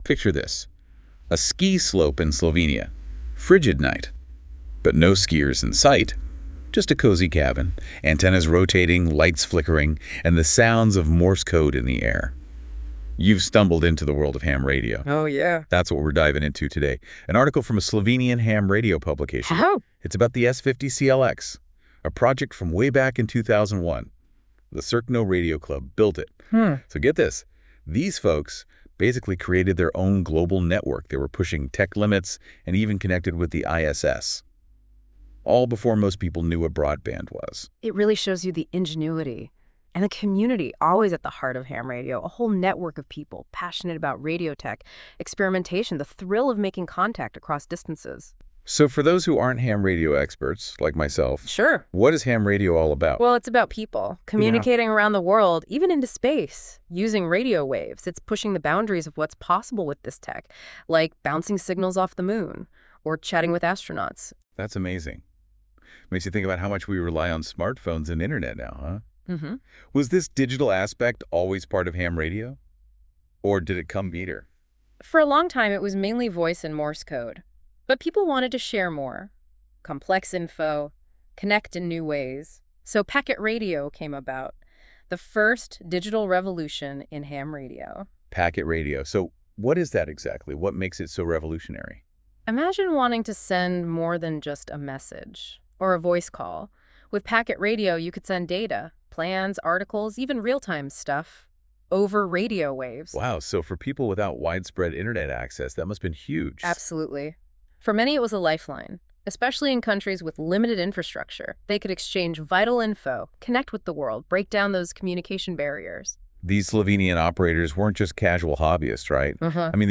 Amateur Radio (Ham Radio) S50CLX DX Cluster spotting server transcript is created with NotebookLM an advanced artificial intelligence (AI) system developed by Google.
Prepis, povzetek v Slovenščini: V tem zapisu je obravnavan projekt S50CLX , ki ga je leta 2001 razvil Radio klub Cerkno v Sloveniji. Govorniki preučujejo tehnične vidike, izzive in vpliv tega inovativnega DX Cluster strežnika.